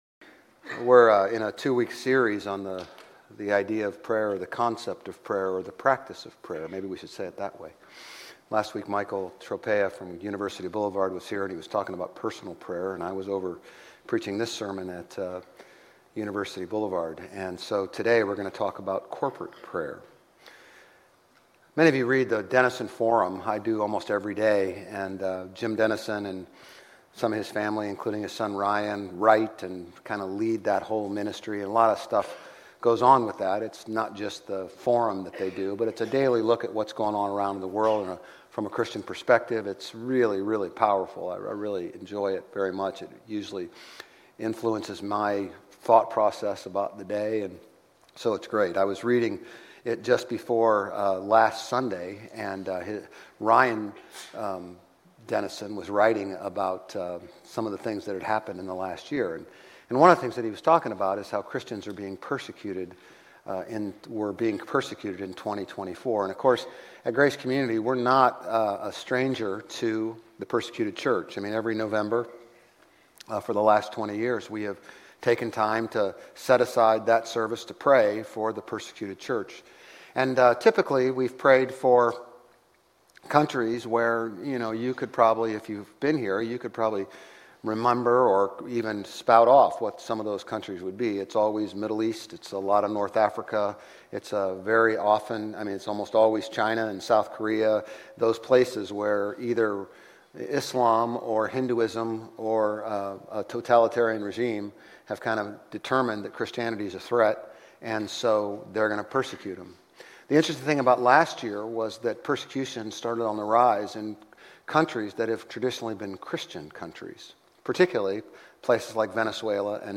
Grace Community Church Old Jacksonville Campus Sermons Jan 12 - Prayer Jan 13 2025 | 00:34:34 1x 00:00 / 00:34:34 Subscribe Share RSS Feed Share Link Embed